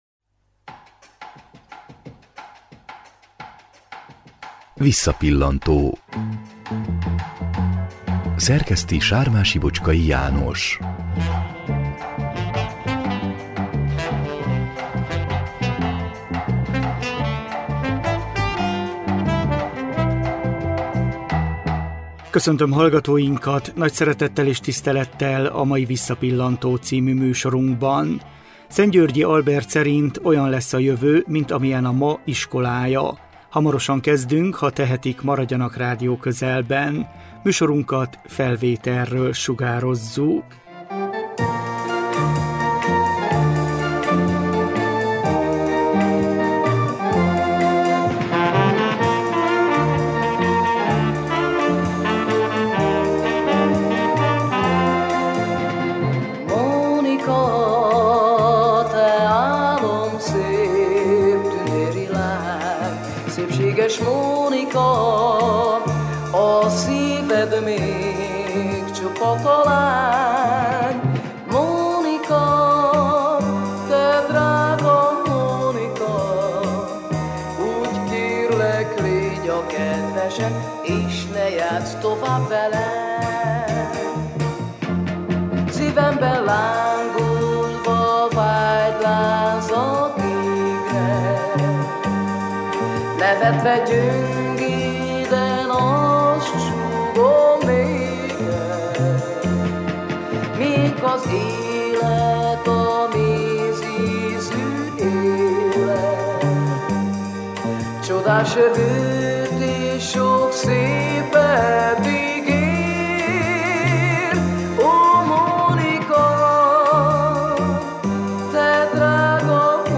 Zoltán Erika magyarországi énekes karierrje sok buktatóval kezdődött, de ő kitartóan hitt a sikerben. Erről beszélgettünk vele 2011-ben.